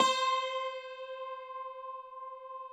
53r-pno14-C3.wav